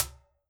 Washingmachine.wav